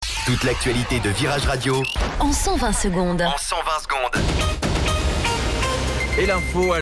Flash Info Lyon